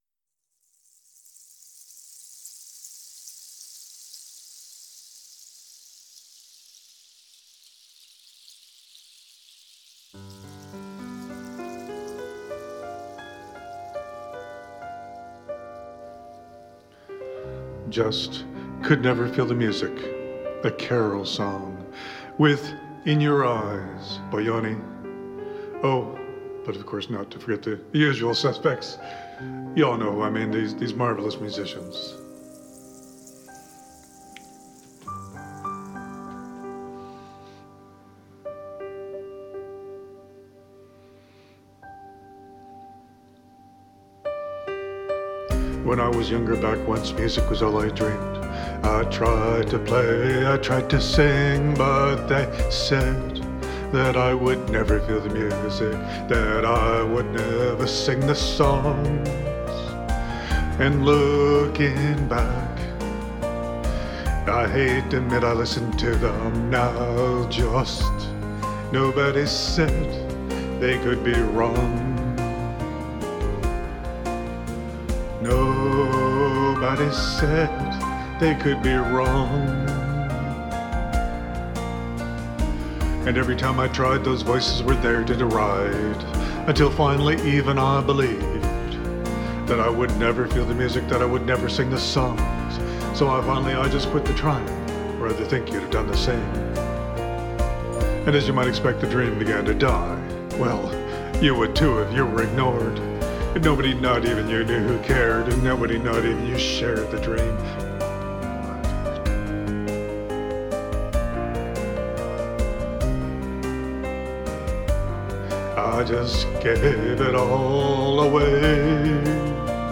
Just could never feel, the 4th karosong to start channeling, started on the guitar by 'finding' an interesting chord sequence based around a B-minor chord.
That's not an earth-shaking revelation (even with the Bachman-ish st-st-st-utter, the song's musical signature).